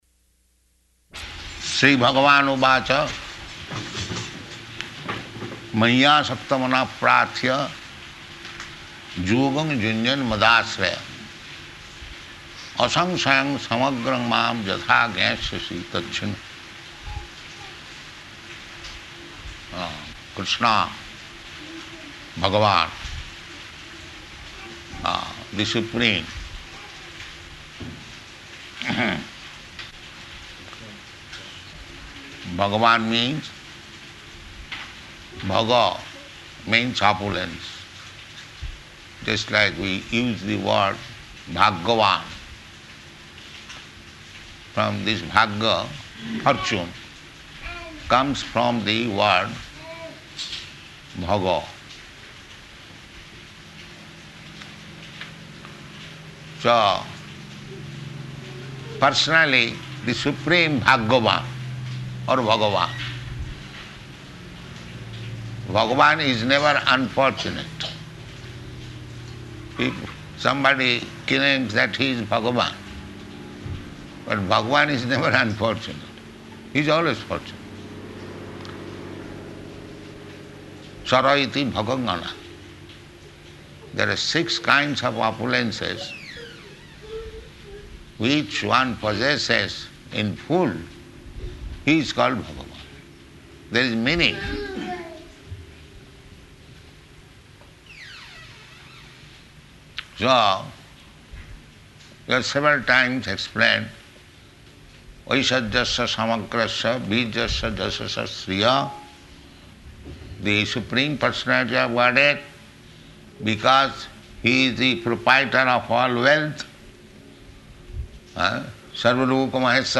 Type: Bhagavad-gita
Location: Nairobi